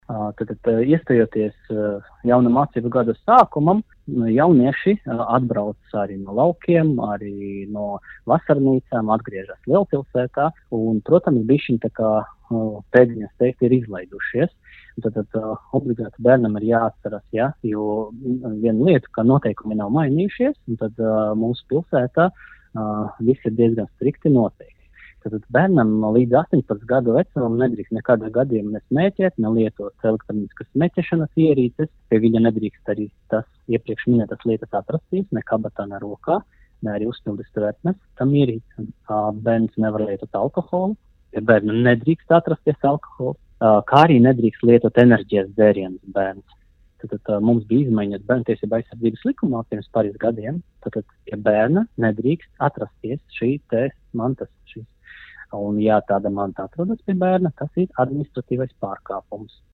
RADIO SKONTO Ziņās par skolēnu kaitīgajiem ieradumiem